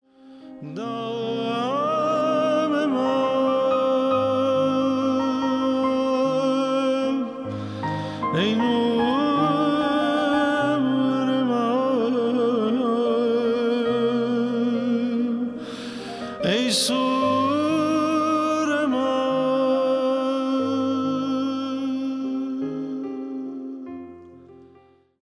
Nous sommes un duo d’homme, Multi-Instrumentalistes.